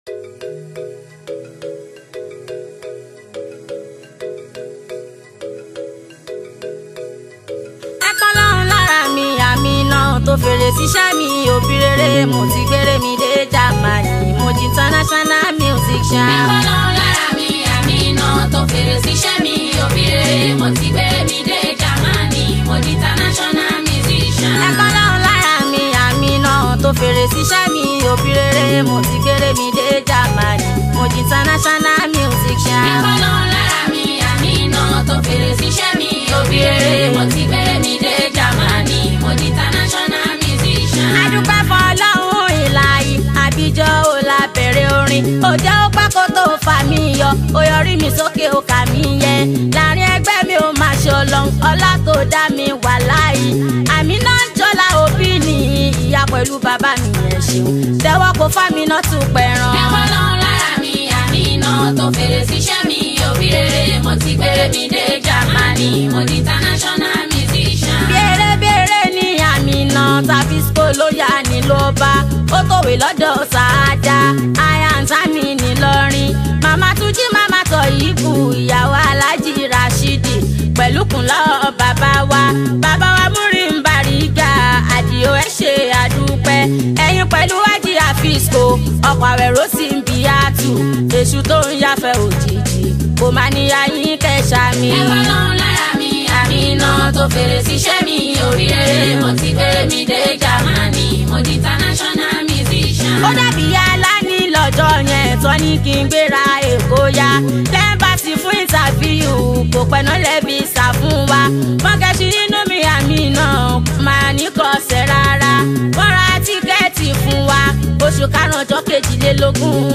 Yoruba Islamic Music 0
Nigerian Yoruba Fuji track
especially if you’re a lover of Yoruba Fuji Sounds